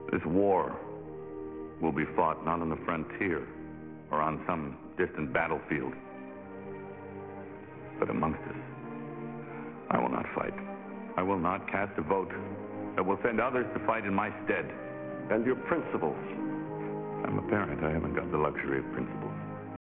nofightspeech.wav